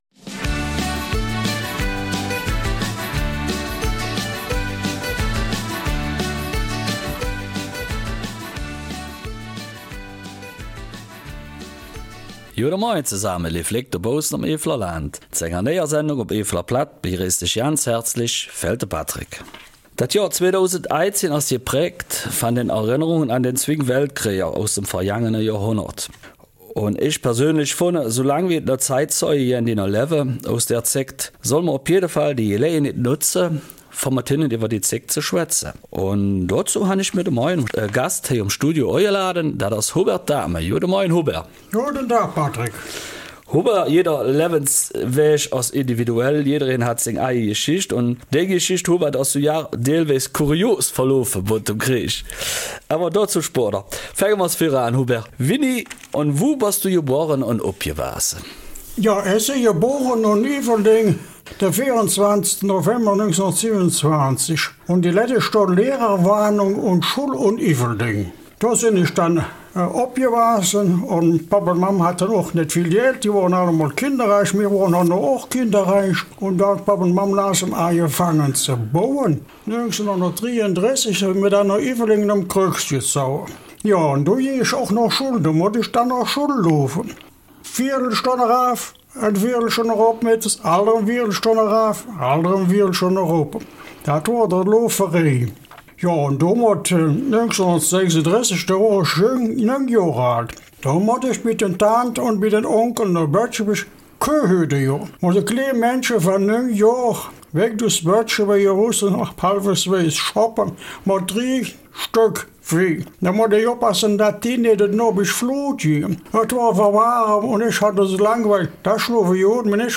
Eifeler Mundart: Erinnerungen aus der Kriegszeit